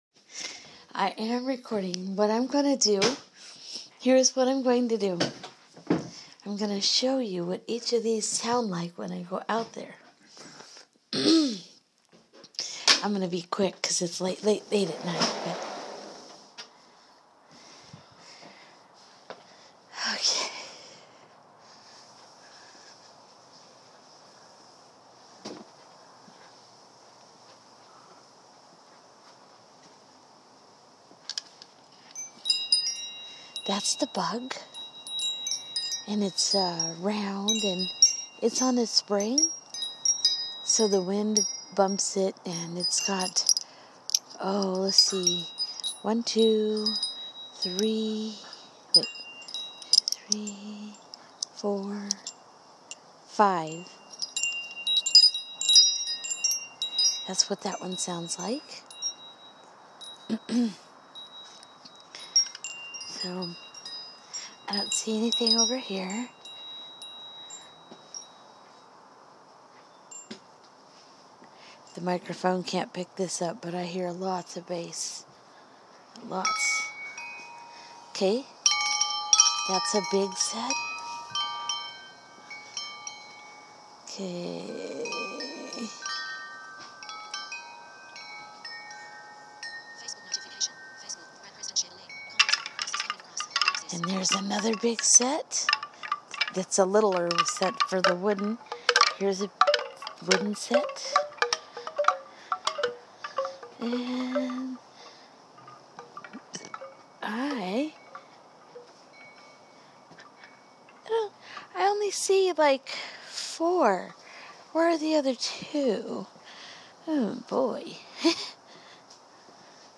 Chimes and chatter
I show all of the chimes and discover that I need to grab another one for the back yard.